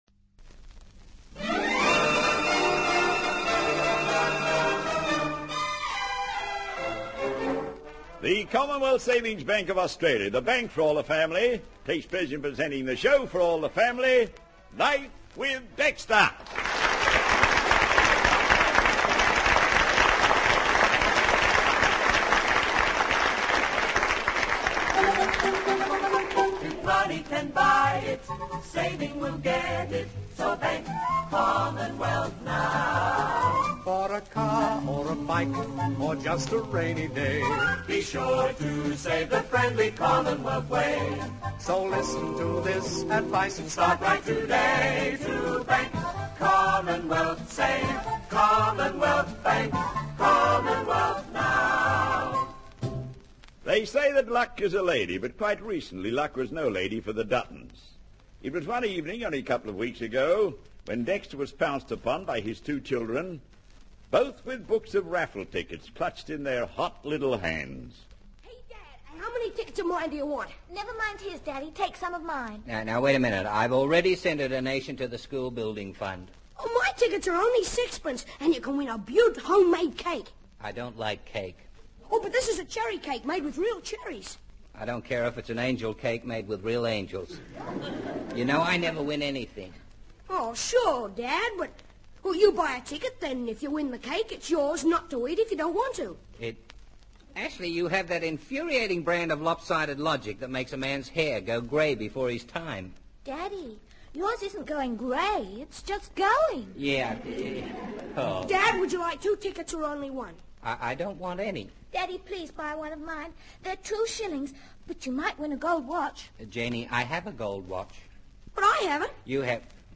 "Life with Dexter" was a popular Australian radio comedy program that aired from the 1950s through to the mid-1960s.